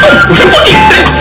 combobreak-old1.wav